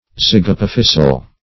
-- Zyg`ap*o*phys"i*al , a. [1913 Webster]
zygapophysial.mp3